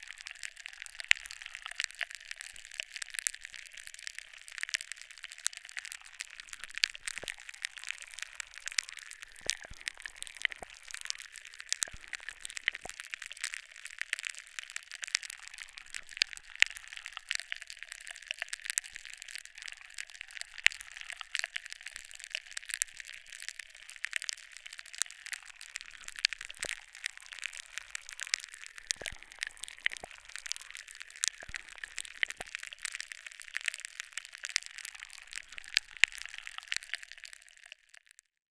Popping Candy sound
Este es el sonido de los caramelos carbonatados en acción con la saliva.
[ENG] This is the sound of carbonated candy in action with saliva.
petazetas-sound.wav